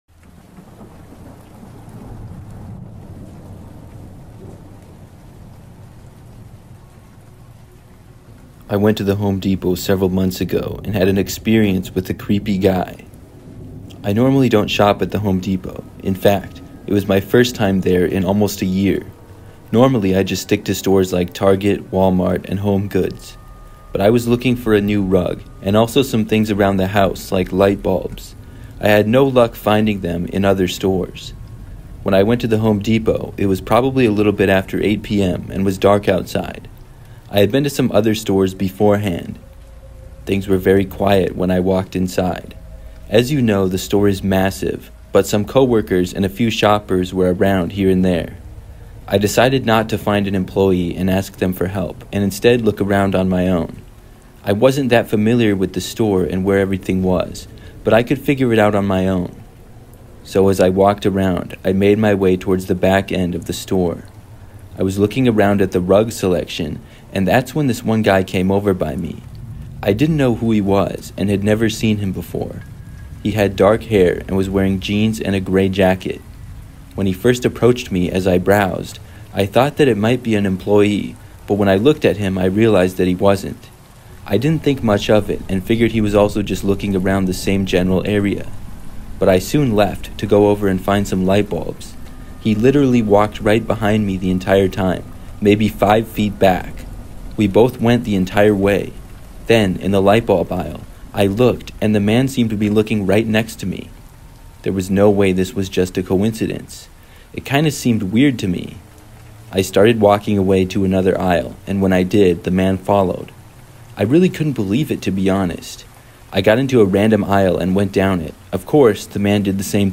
True Thanksgiving Horror Stories (With Rain Sounds) That Will Change Your View of the Holiday